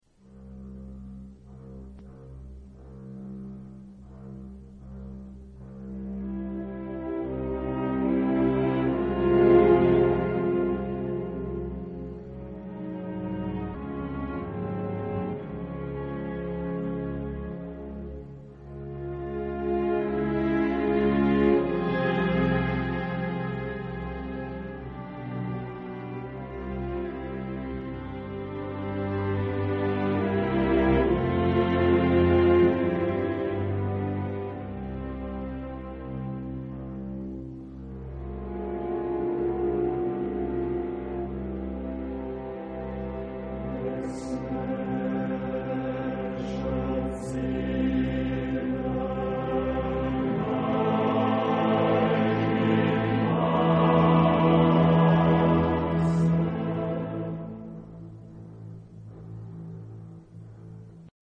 Género/Estilo/Forma: Motete ; Profano
Tipo de formación coral: TTTTBBBB  (8 voces Coro de hombres )
Instrumentación: Cuerdas  (5 partes instrumentales)
Instrumentos: Viola (2) ; Violonchelo (2) ; Contrabajo (1)
Tonalidad : do mayor